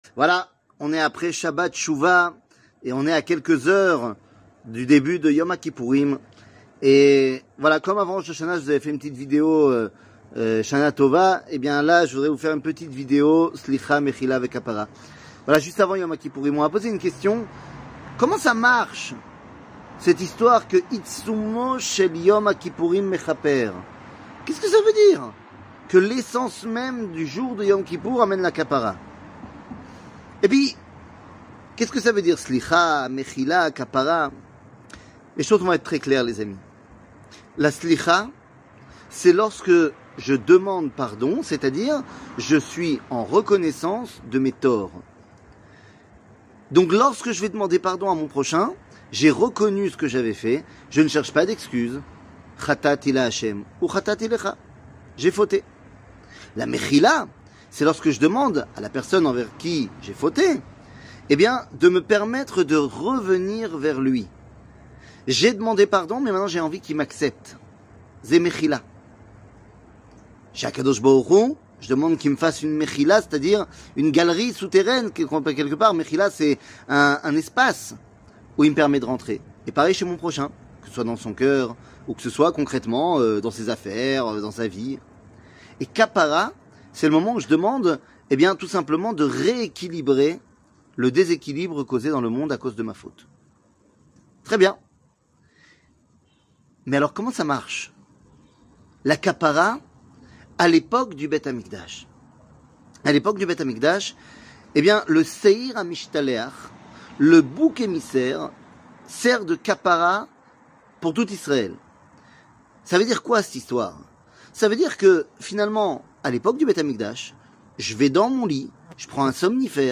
Yom Kippour , Sliha, Mehila et Kapara 00:05:53 Yom Kippour , Sliha, Mehila et Kapara שיעור מ 24 ספטמבר 2023 05MIN הורדה בקובץ אודיו MP3 (5.37 Mo) הורדה בקובץ וידאו MP4 (12.44 Mo) TAGS : שיעורים קצרים